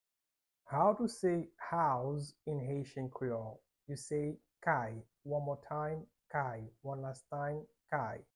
How to say "House" in Haitian Creole - "Kay" pronunciation by a native Haitian teacher
“Kay” Pronunciation in Haitian Creole by a native Haitian can be heard in the audio here or in the video below:
How-to-say-House-in-Haitian-Creole-Kay-pronunciation-by-a-native-Haitian-teacher.mp3